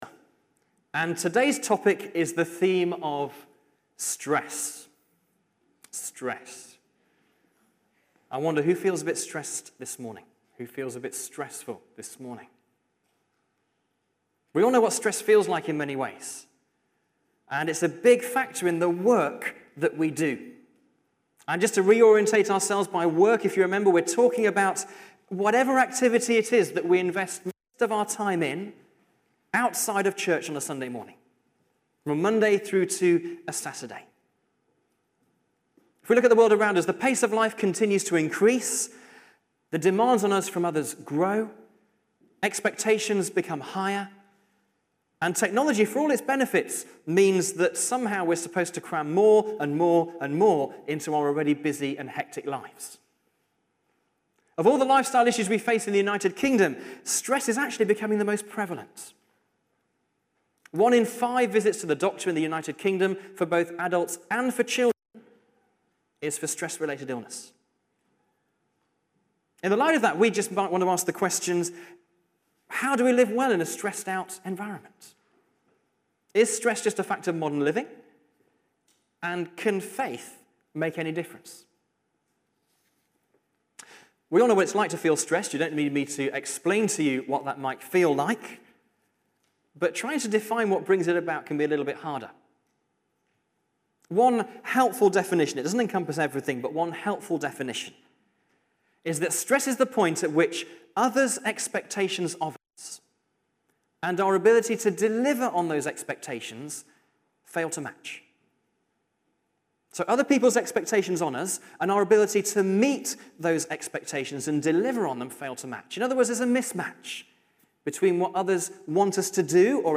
Sermon_1March2015.mp3